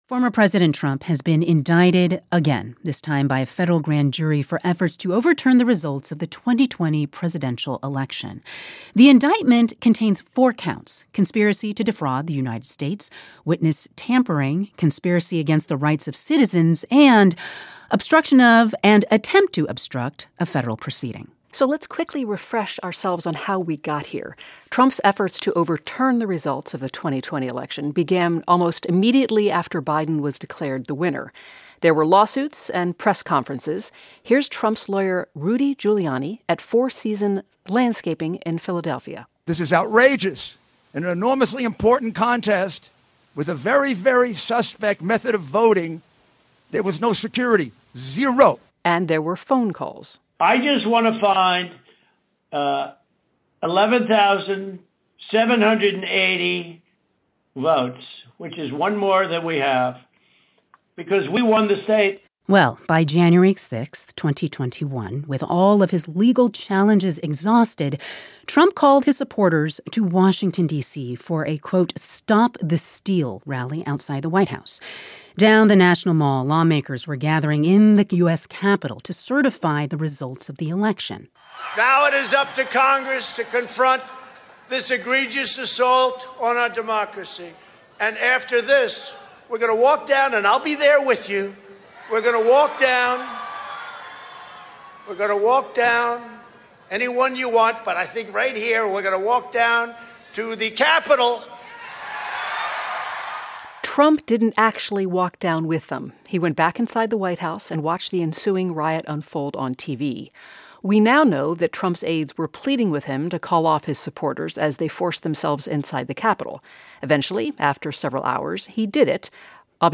NPR's Ailsa Chang talks with presidential historian Tim Naftali about the significance of Trump's latest indictment for his role in the insurrection at the U.S. Capitol on Jan. 6.